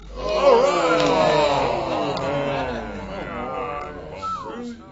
crowd_disappointment.wav